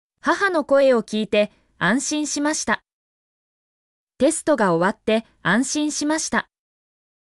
mp3-output-ttsfreedotcom-2_lZrEKWKe.mp3